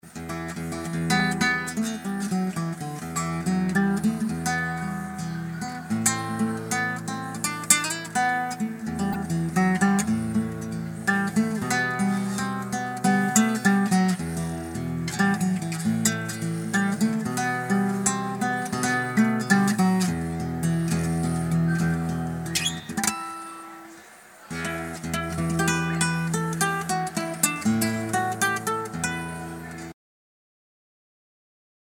recorded IN CONCERT